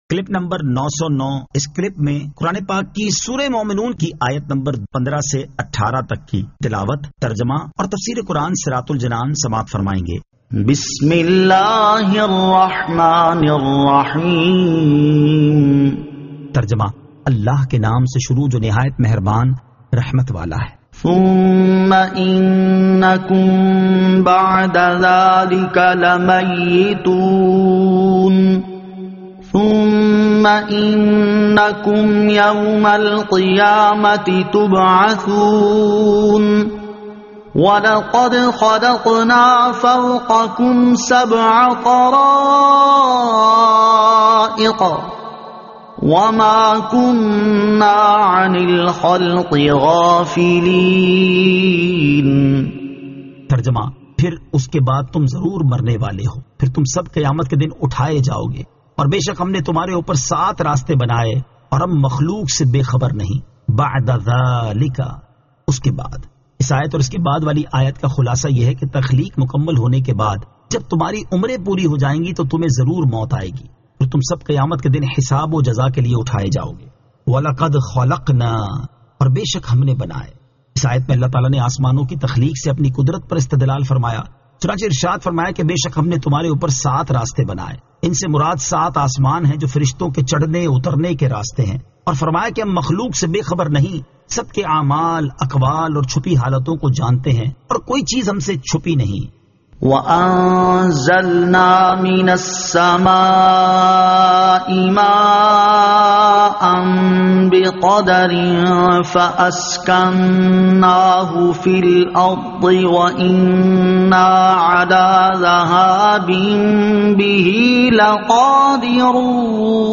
Surah Al-Mu'minun 15 To 18 Tilawat , Tarjama , Tafseer
2022 MP3 MP4 MP4 Share سُوَّرۃُ المُؤٗمِنُوٗنِ آیت 15 تا 18 تلاوت ، ترجمہ ، تفسیر ۔